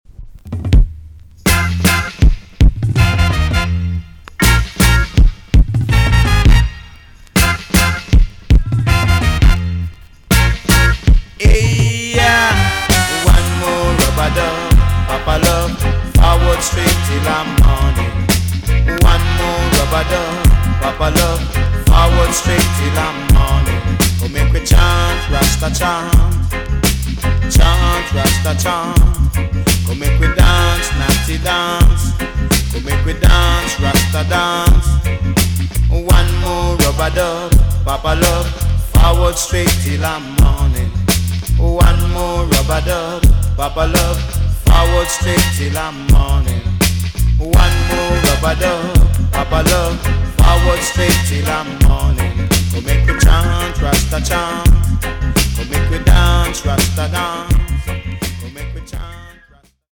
TOP >DISCO45 >80'S 90'S DANCEHALL
EX- 音はキレイです。
WICKED KILLER DANCEHALL TUNE!!